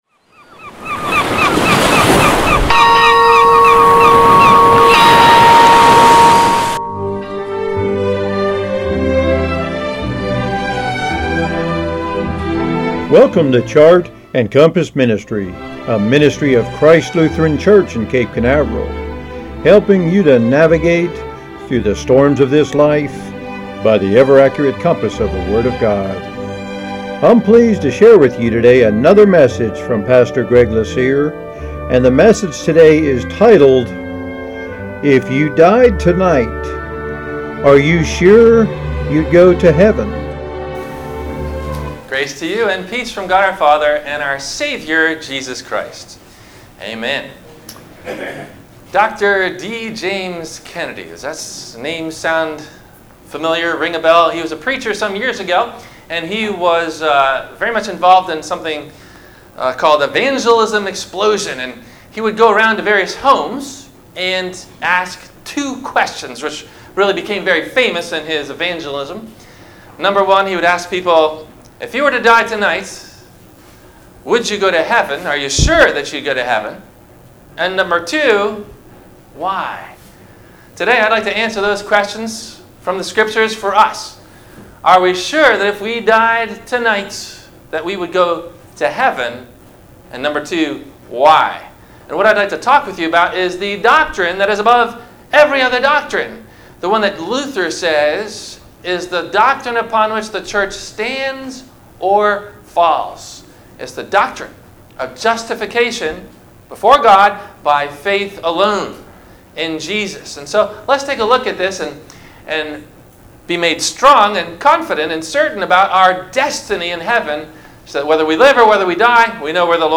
– WMIE Radio Sermon – October 01 2018 - Christ Lutheran Cape Canaveral